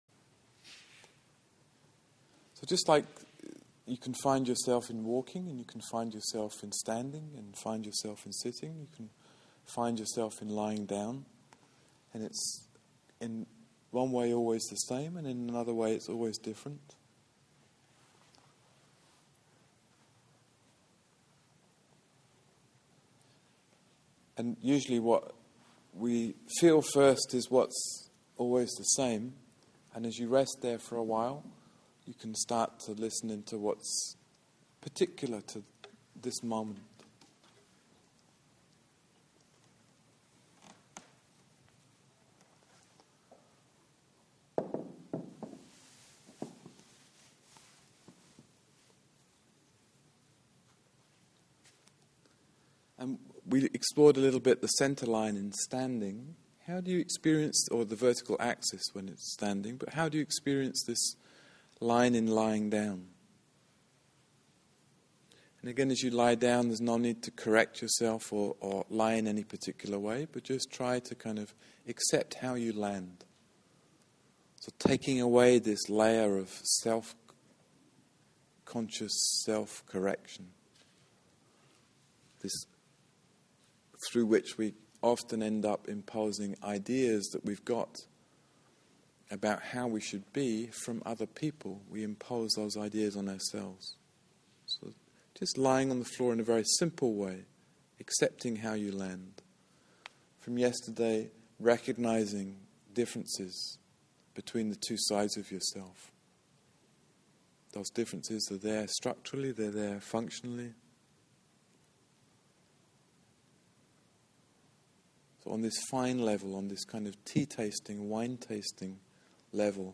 idocde: Feldenkrais ATM class - Turning around the axis